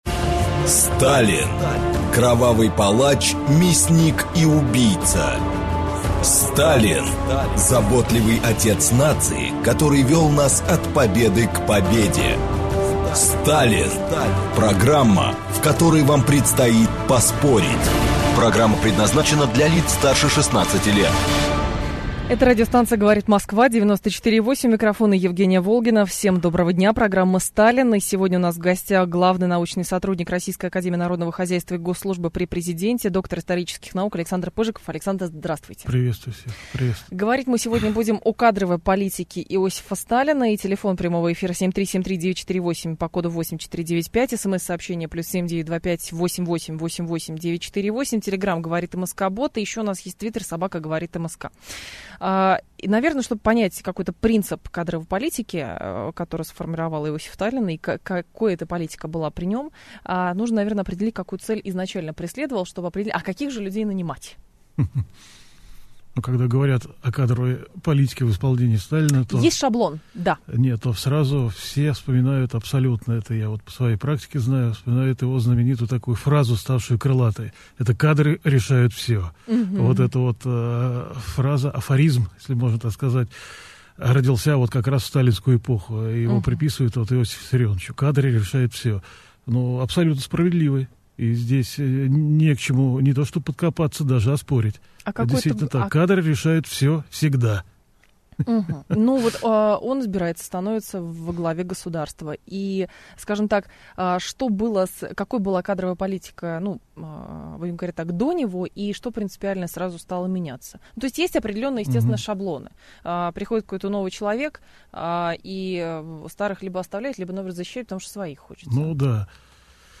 Аудиокнига Кадровая политика Сталина | Библиотека аудиокниг